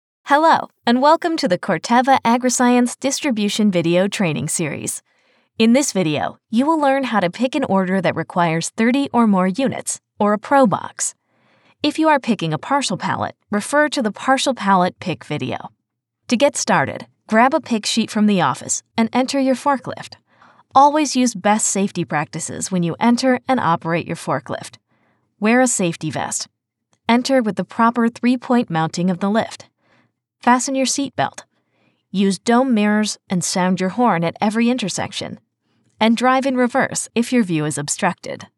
Commercieel, Natuurlijk, Vriendelijk, Warm, Zakelijk
E-learning